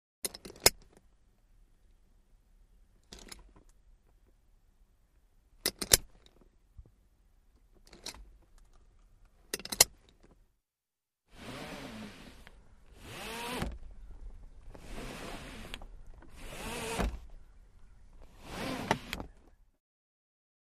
Car Seat belt Movement; Fastening And Unfastening Seat belt Buckle, Sliding Fabric Through Buckle To Adjust Length, Extending And Retracting Seat Belt. Close Perspective.